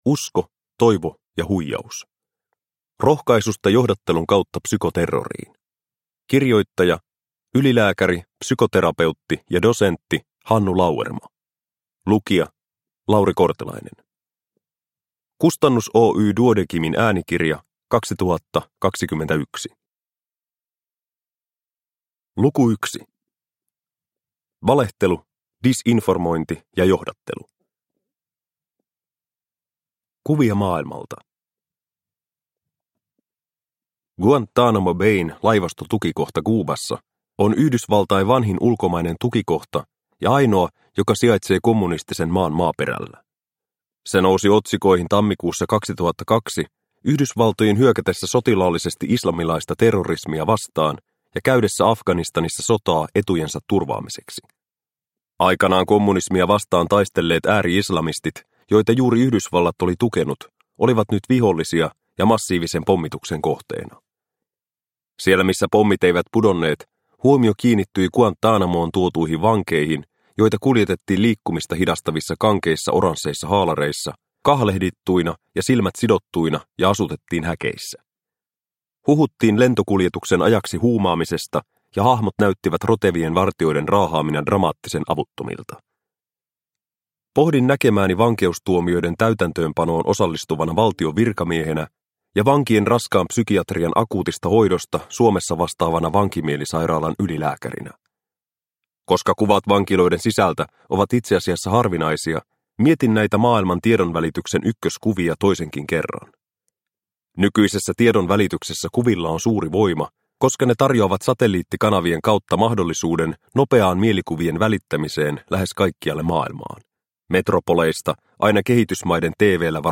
Usko, toivo ja huijaus – Ljudbok – Laddas ner